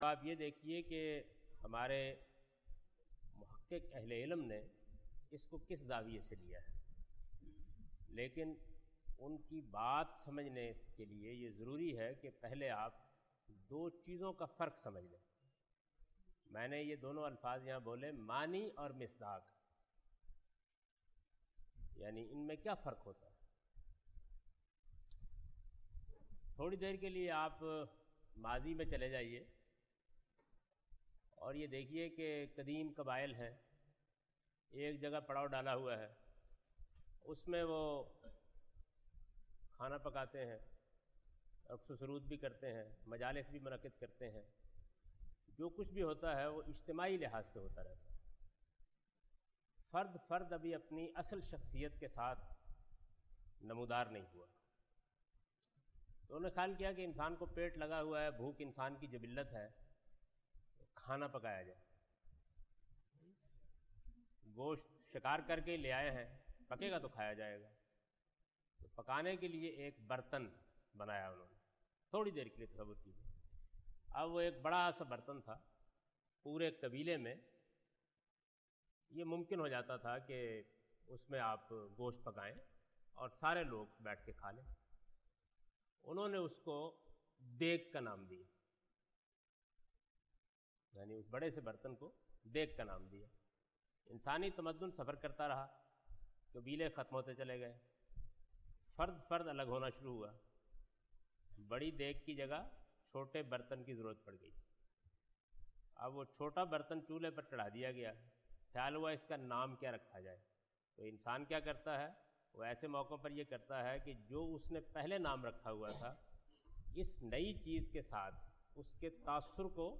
A comprehensive course on Islam, wherein Javed Ahmad Ghamidi teaches his book ‘Meezan’.
In this lecture he teaches the intentionality of the text Muhkam and Mutashabih in Quran. (Lecture no.30 – Recorded on 26th April 2002)